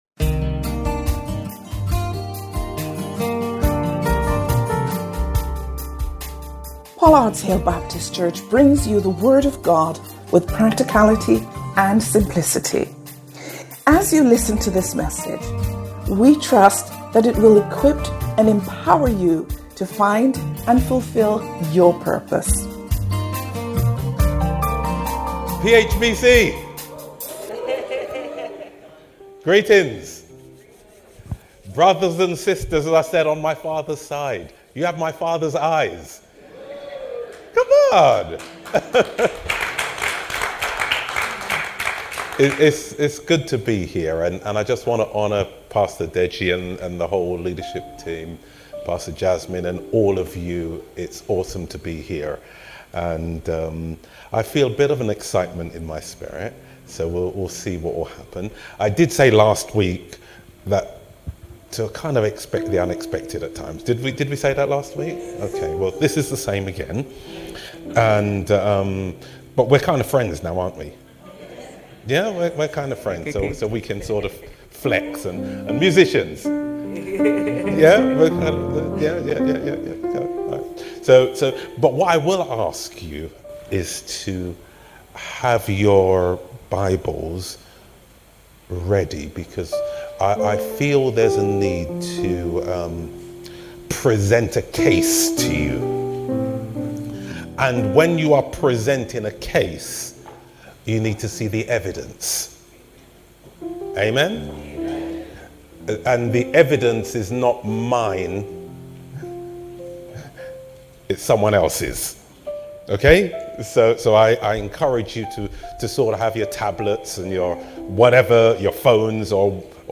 Sermons – Pollards Hill Baptist Church